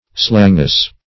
slangous - definition of slangous - synonyms, pronunciation, spelling from Free Dictionary Search Result for " slangous" : The Collaborative International Dictionary of English v.0.48: Slangous \Slan"gous\, a. Slangy.